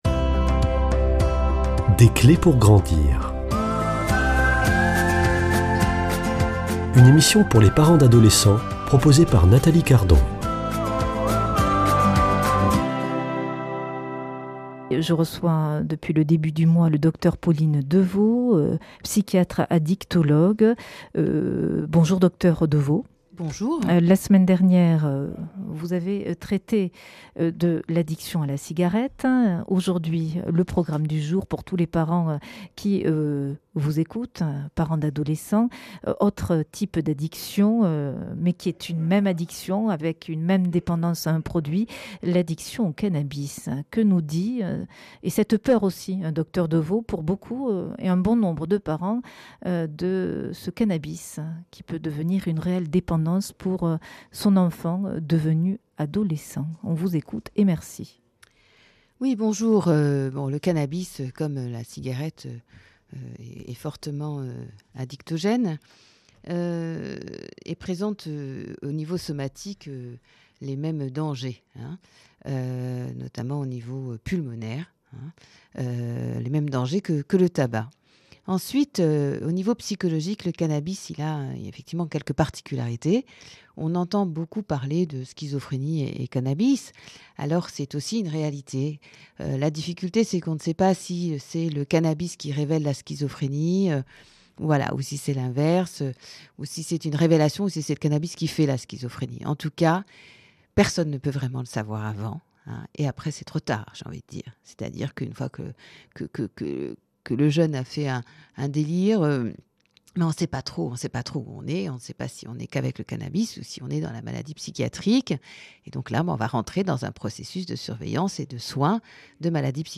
Une émission présentée par
Journaliste